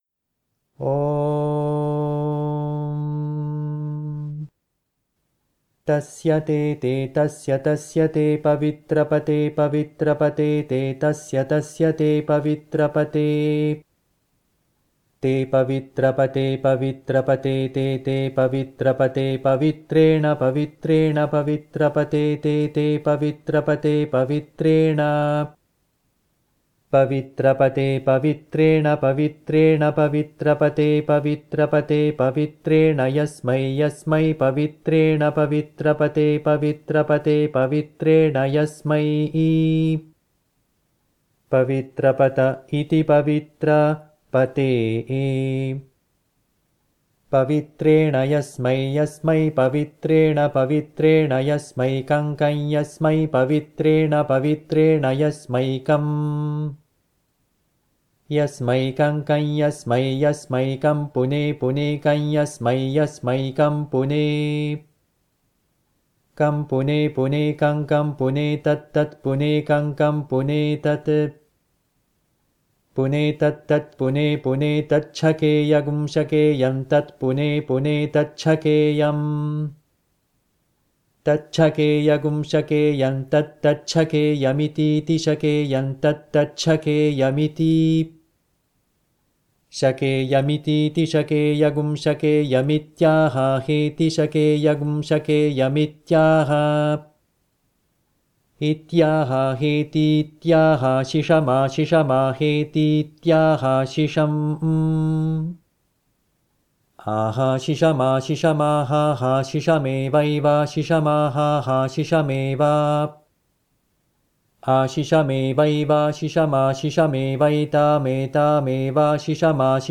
tasya te pavitrapate - ghana paaThaH.mp3